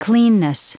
Prononciation du mot cleanness en anglais (fichier audio)
Prononciation du mot : cleanness